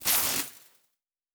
pgs/Assets/Audio/Sci-Fi Sounds/Electric/Spark 12.wav at master
Spark 12.wav